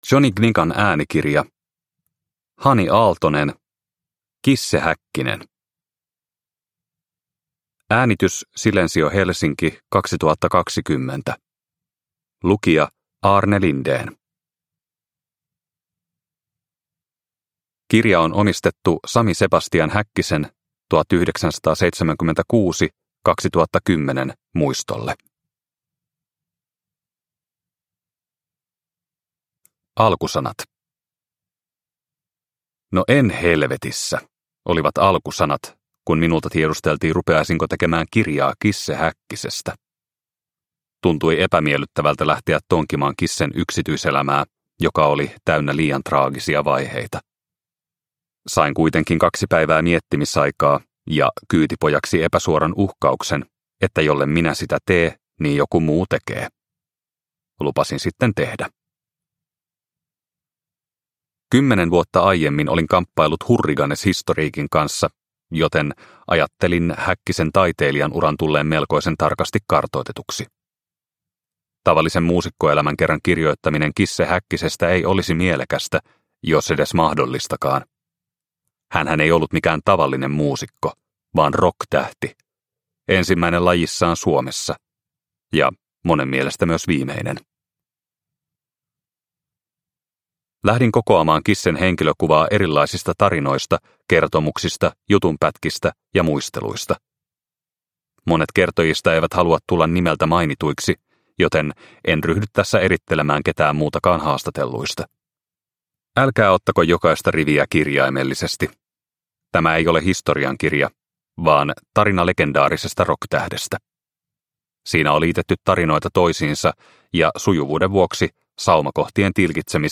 Cisse Häkkinen – Ljudbok